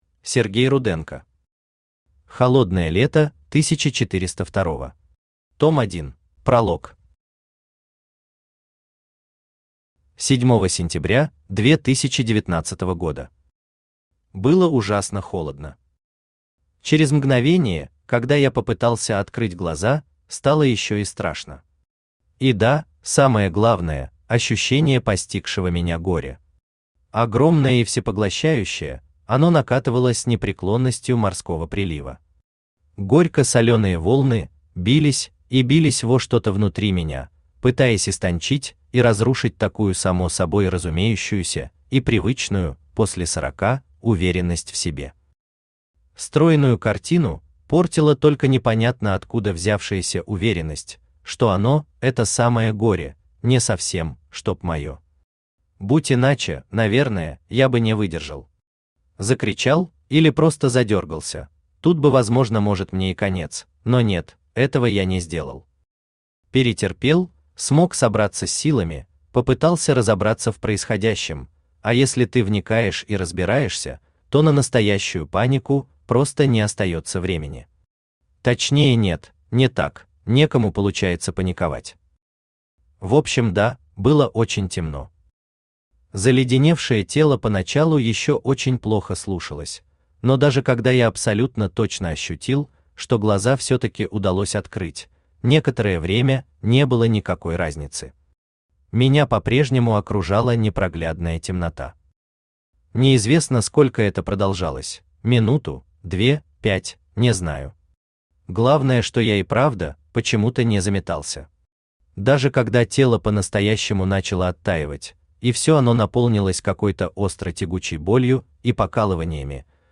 Аудиокнига Холодное лето 1402-го. Том 1 | Библиотека аудиокниг
Том 1 Автор Сергей Владимирович Руденко Читает аудиокнигу Авточтец ЛитРес.